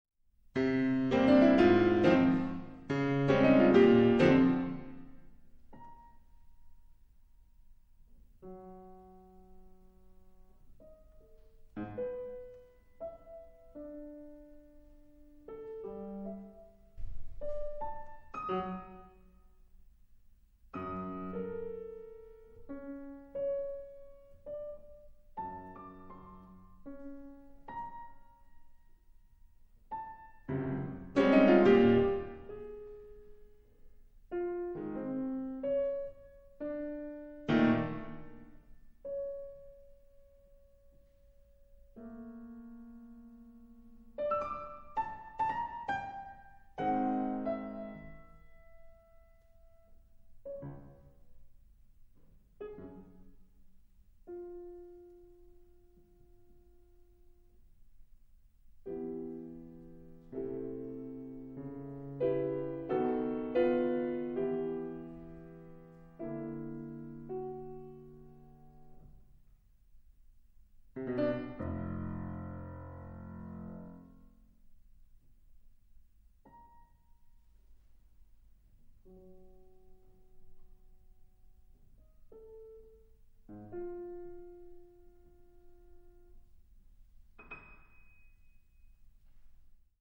Drei Klavierstücke
07.1994 / UA 08.07.1994, Lübeck, Musikhochschule